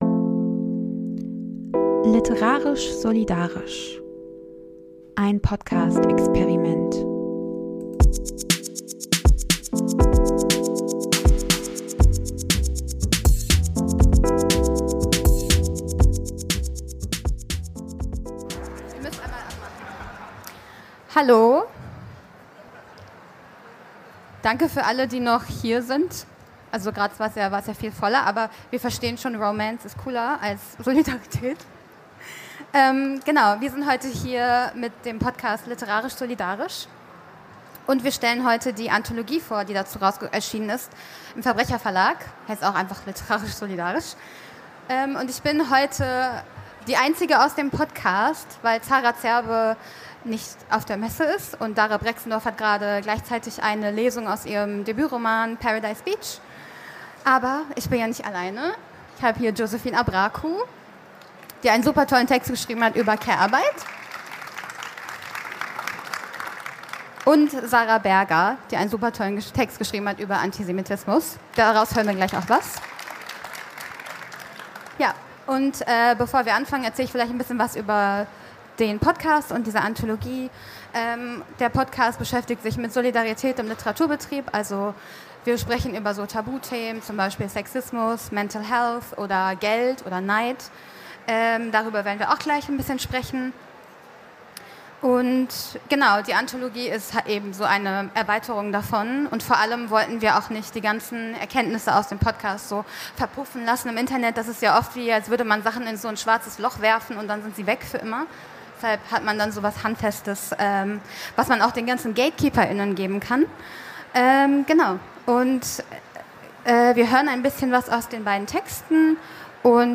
Live von der LBM26.